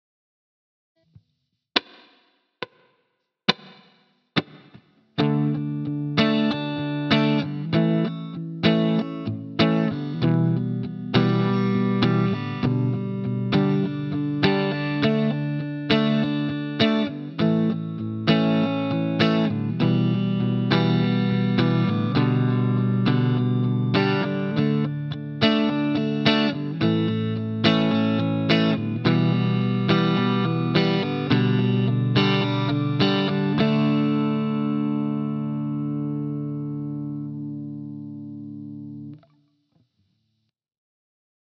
Ici les croches sont jouées toutes vers le bas
Rythmique 1 :